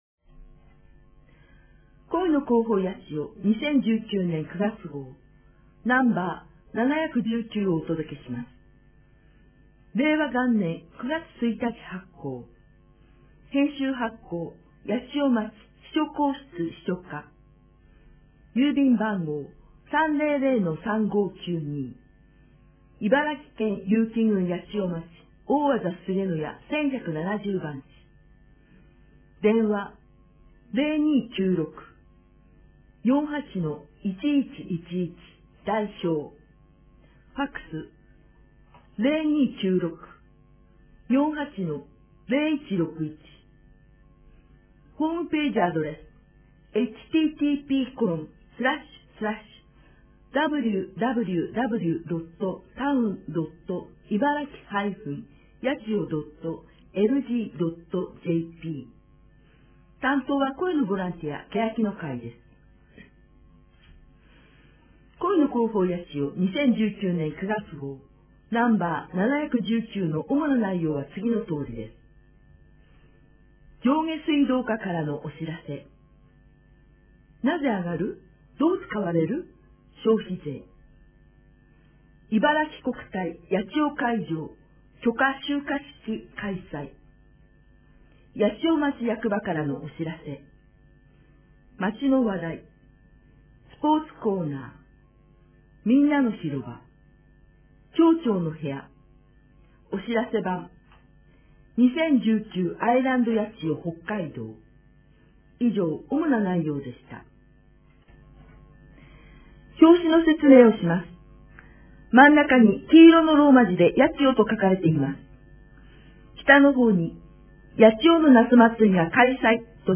声の広報やちよは、「けやきの会」のご協力により、目の不自由な方に音声の「広報やちよ」としてお届けします。